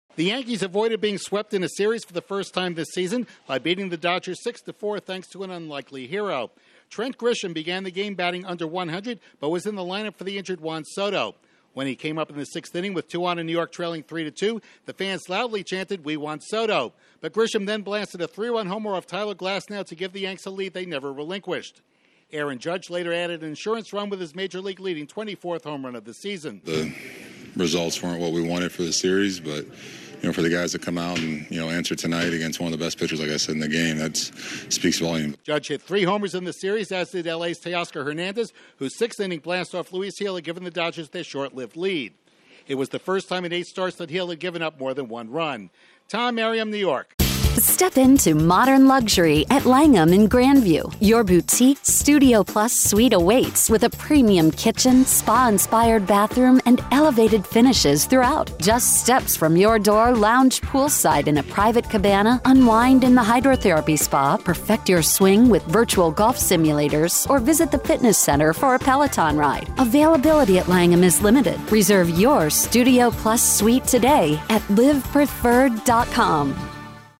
The Yankees get a getaway win over the Dodgers. Correspondent